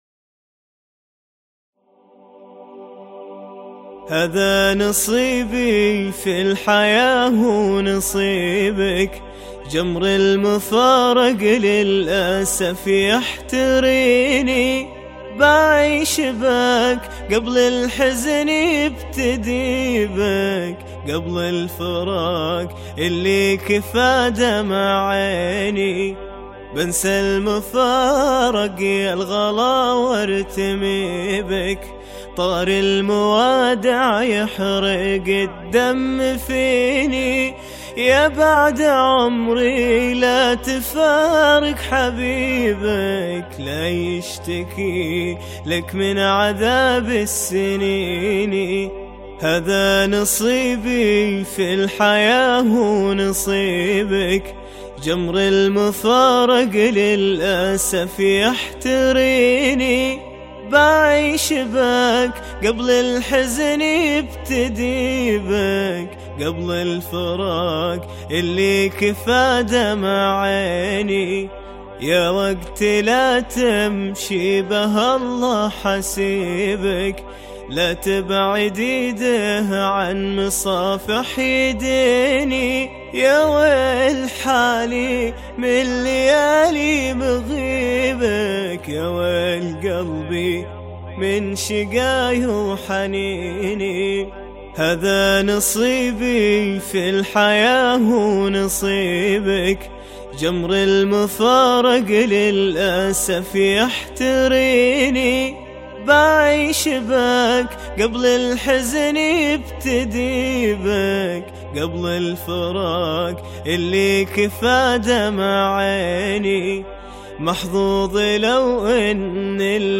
شيلة